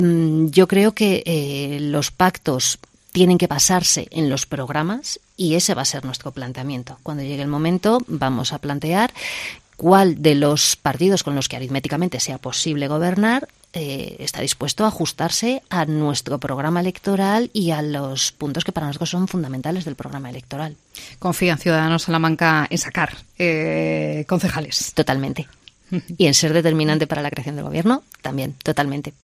entrevista en COPE SALAMANCA